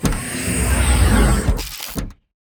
DoorOpen4.wav